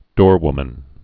(dôrwmən)